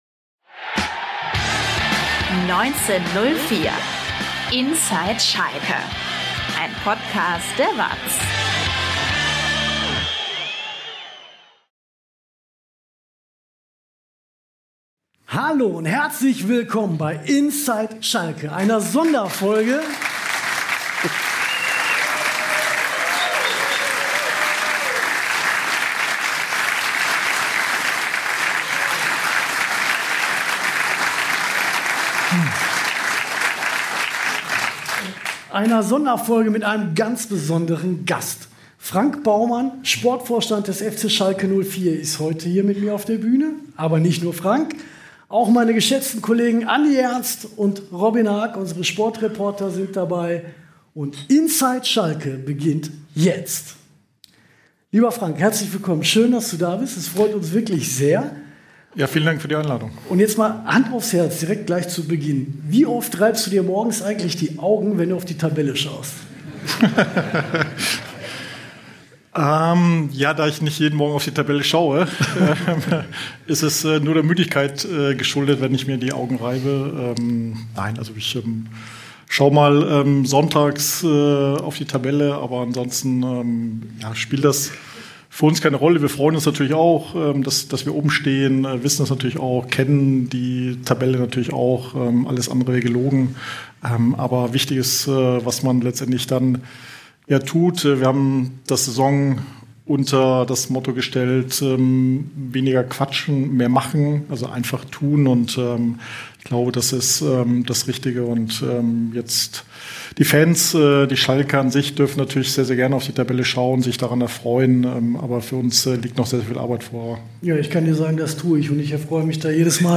live vor Publikum